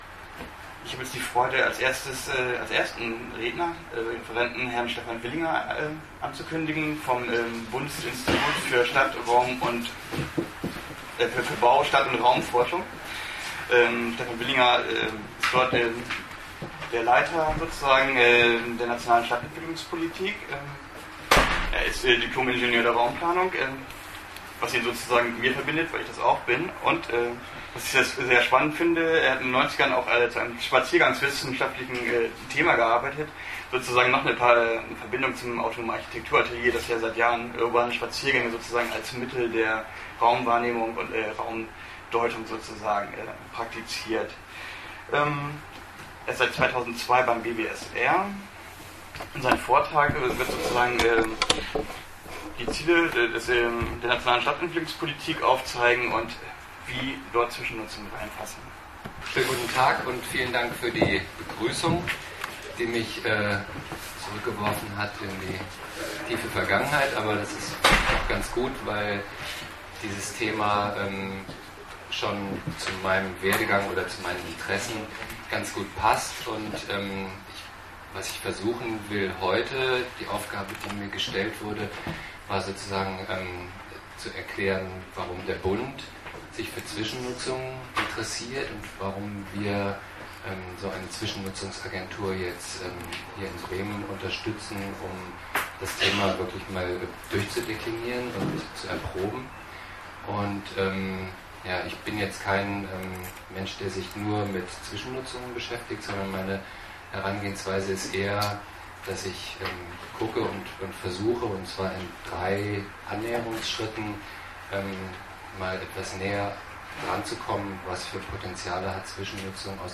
Ergänzend zu der schriftliche Dokumentation können Audiomischnitte der Vorträge das ersten Konferenztages heruntergeladen werden: